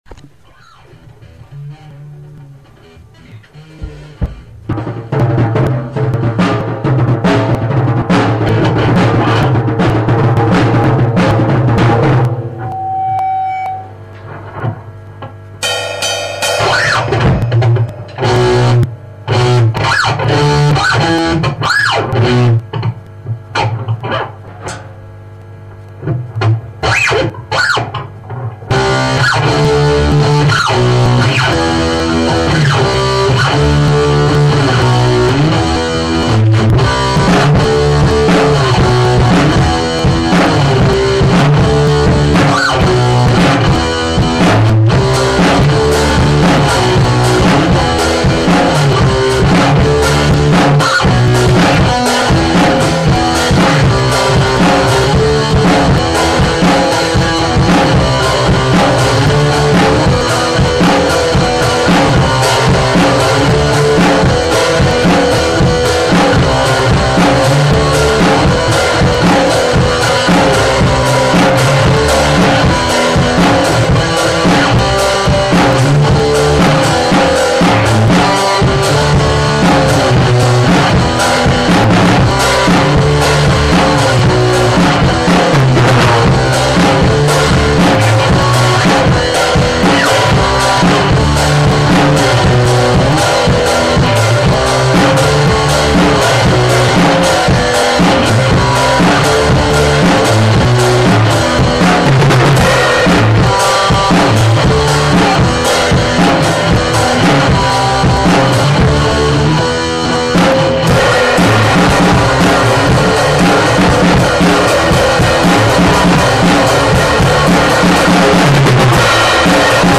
Одна из наших репетиций)